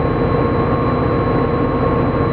F40 IN CAB SOUND
F40incabedit1.wav